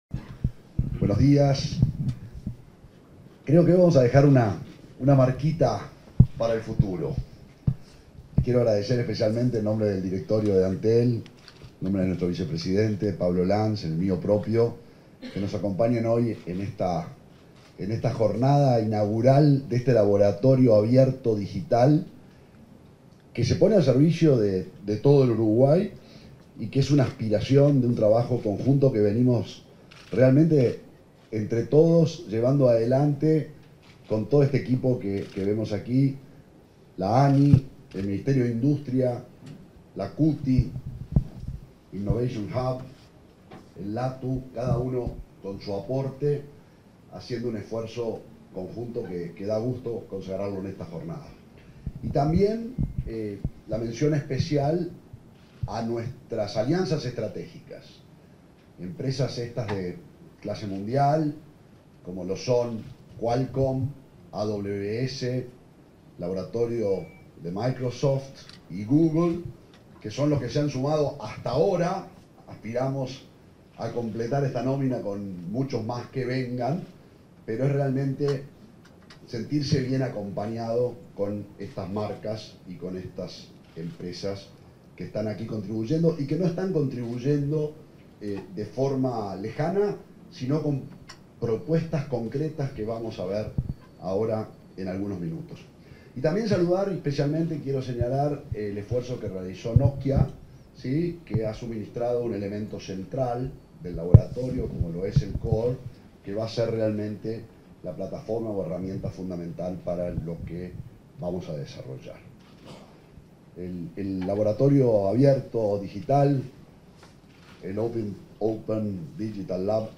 Palabras del presidente de Antel y del ministro de Industria, Energía y Minería
Palabras del presidente de Antel y del ministro de Industria, Energía y Minería 17/10/2023 Compartir Facebook X Copiar enlace WhatsApp LinkedIn En el marco de la presentación del Open Digital Lab, este 17 de octubre, se expresaron el presidente de Antel, Gabriel Gurméndez, y el ministro de Industria, Energía y Minería, Omar Paganini.